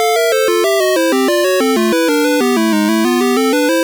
Source Recorded from the Sharp X1 version.